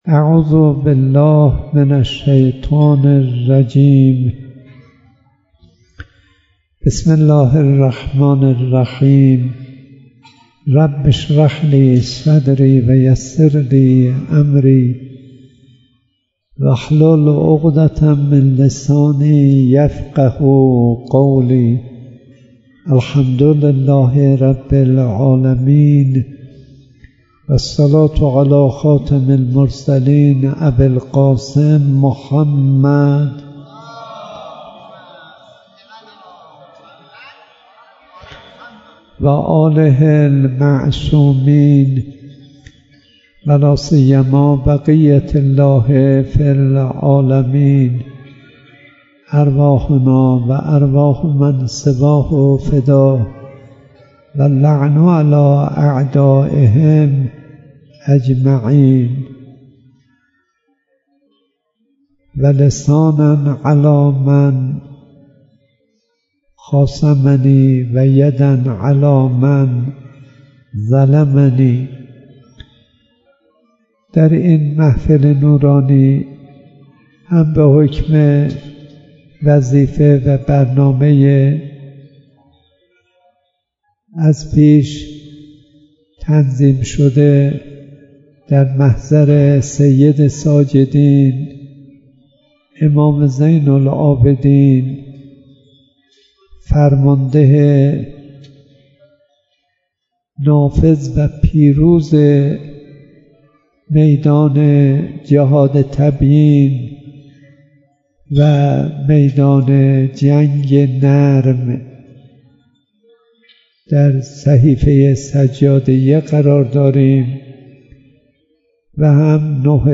سخنرانی شب اوّل ماه رجب و ولادت امام محمّد باقر (علیه السلام)